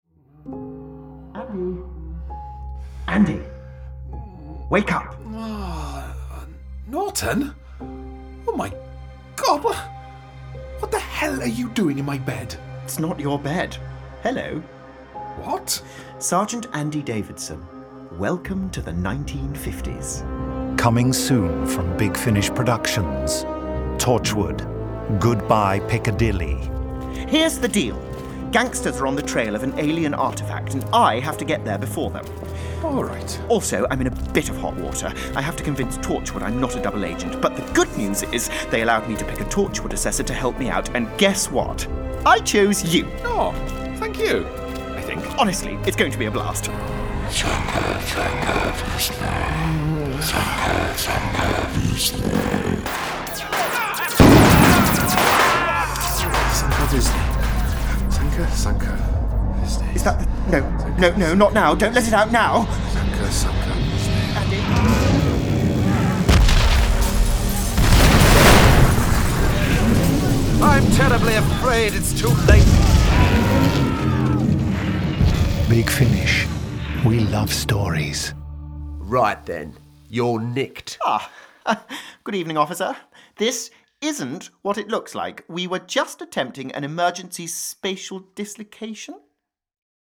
Torchwood - Monthly Range 22. Torchwood: Goodbye Piccadilly Released June 2018 Written by James Goss Starring Samuel Barnett Tom Price This release contains adult material and may not be suitable for younger listeners. From US $10.04 CD + Download US $12.56 Buy Download US $10.04 Buy Save money with a bundle Login to wishlist 48 Listeners recommend this Share Tweet Listen to the trailer Download the trailer